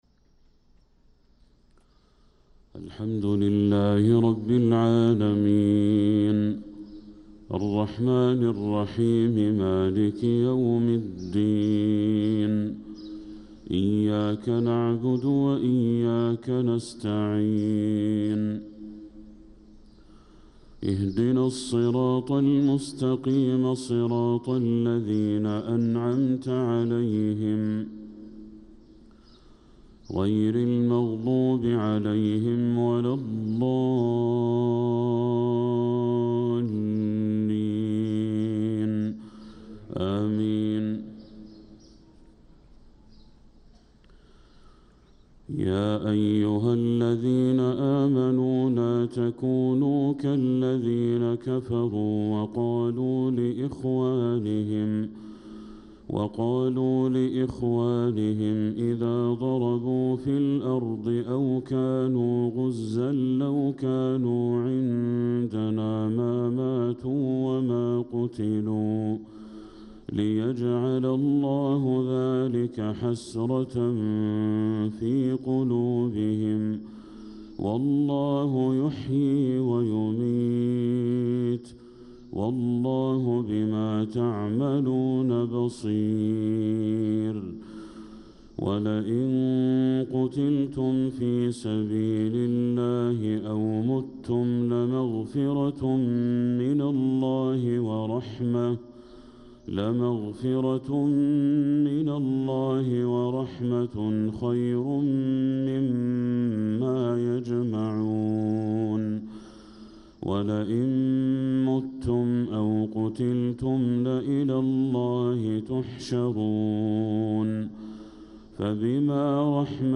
صلاة الفجر للقارئ بدر التركي 28 ربيع الآخر 1446 هـ
تِلَاوَات الْحَرَمَيْن .